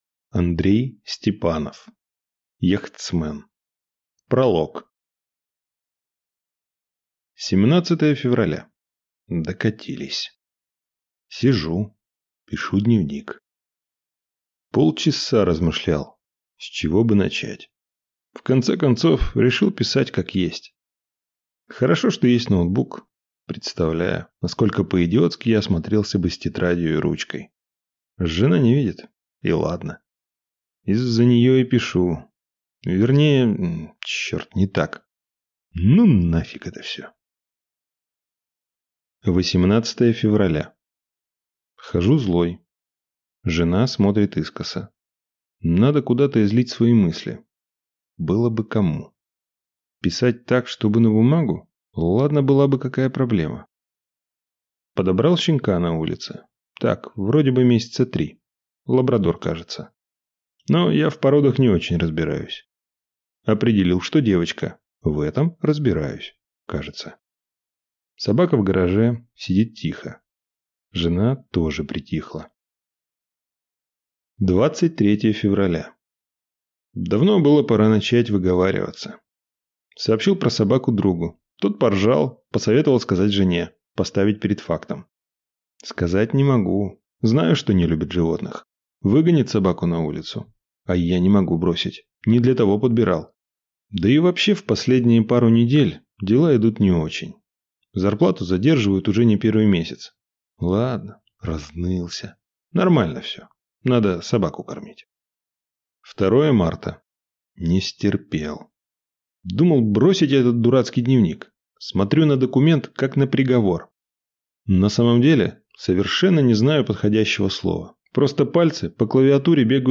Аудиокнига Яхтсмен | Библиотека аудиокниг